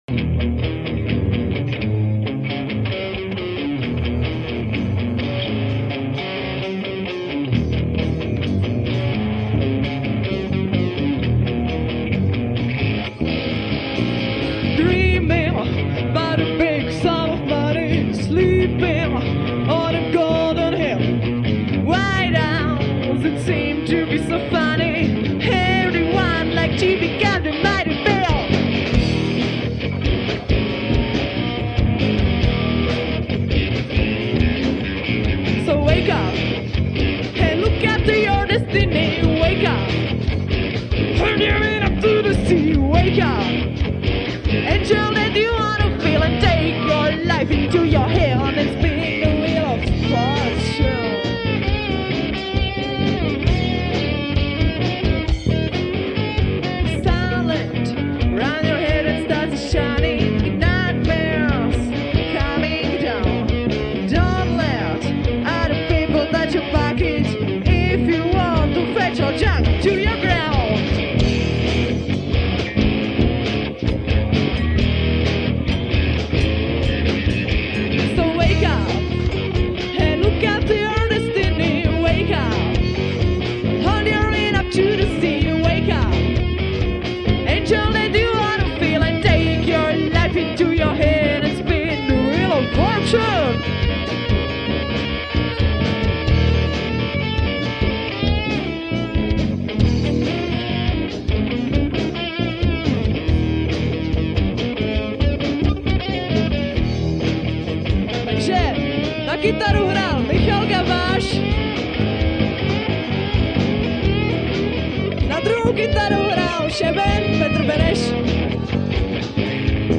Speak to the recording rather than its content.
nahrávky z koncertu (.mp3):